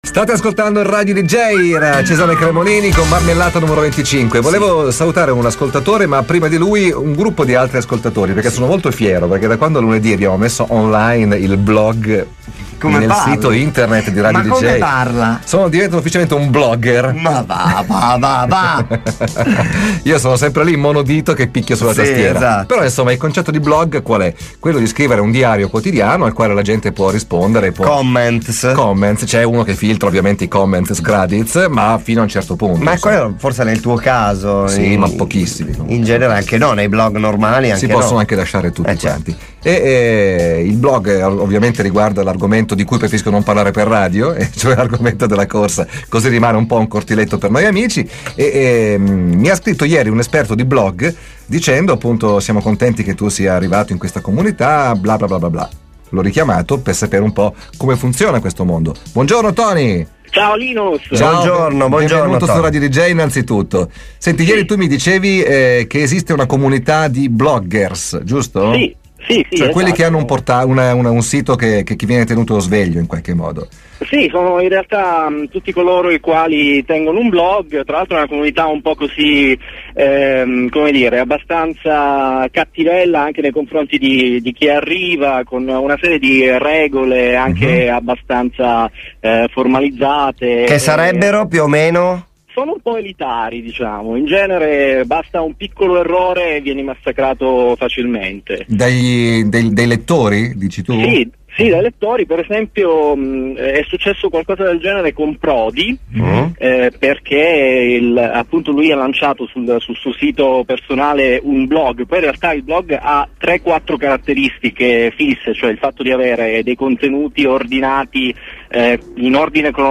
L’intervista è disponibile in